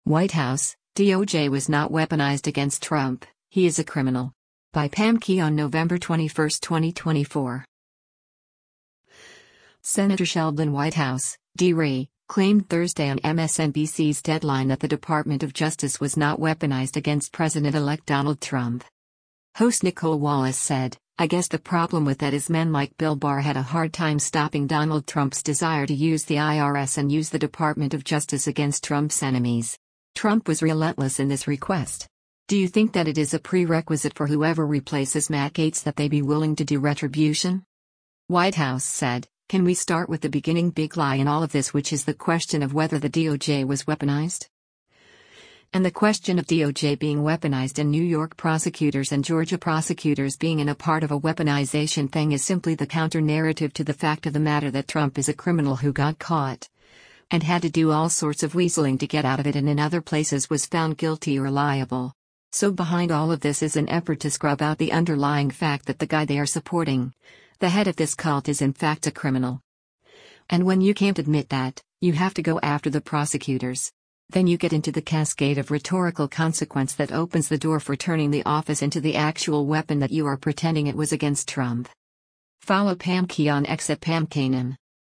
Senator Sheldon Whitehouse (D-RI) claimed Thursday on MSNBC’s “Deadline” that the Department of Justice was not weaponized against President-elect Donald Trump.